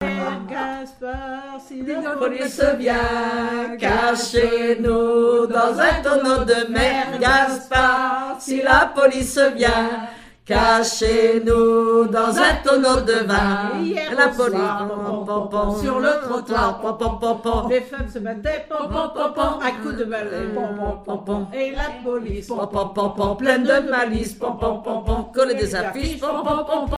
Île-d'Yeu (L')
Enfantines - rondes et jeux
collectif de chanteuses de chansons traditionnelles
Pièce musicale inédite